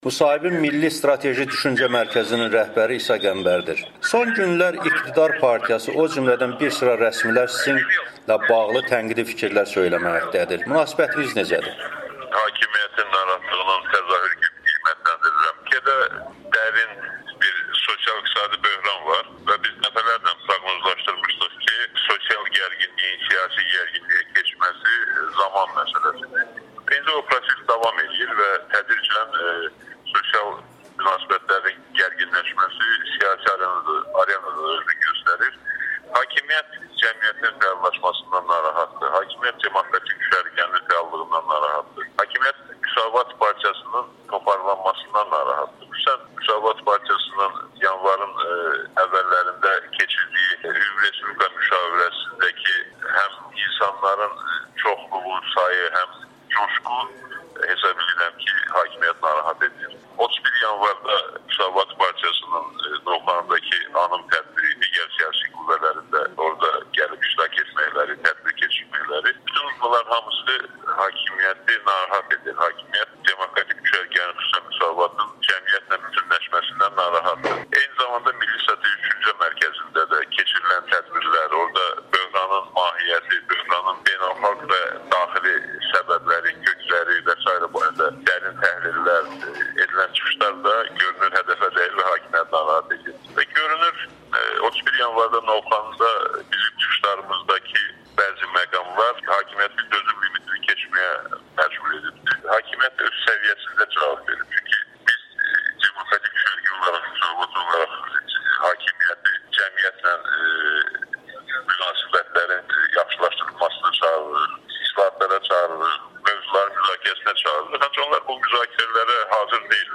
Isa Qəmbərin Amerikanın Səsinə müsahibəsi